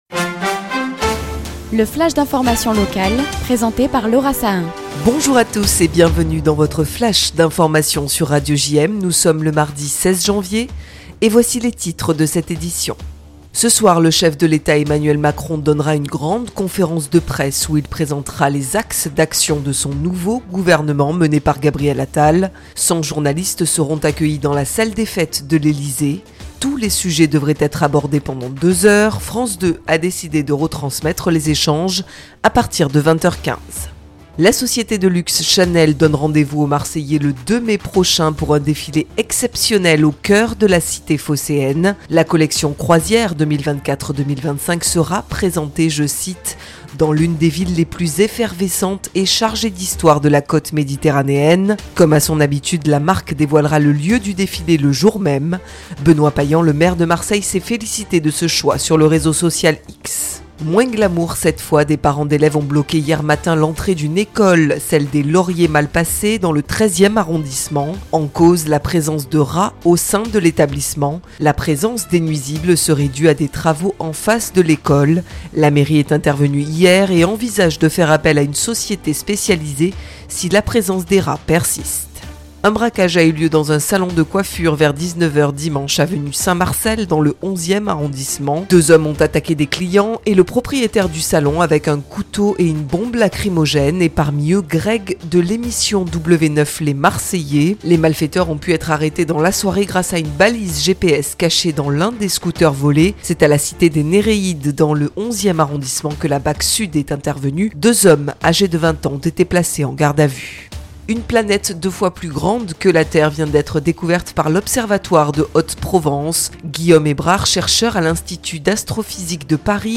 Le Flash Info